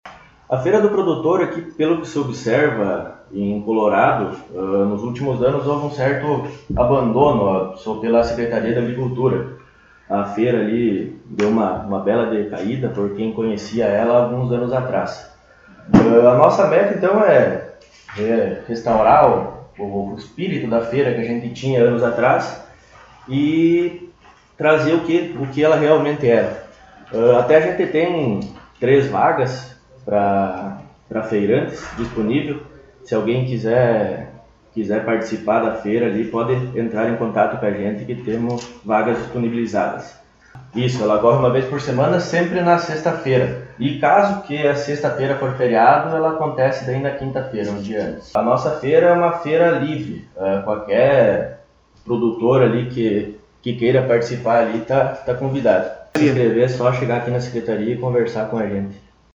Secretário Municipal de Agricultura e funcionários concederam entrevista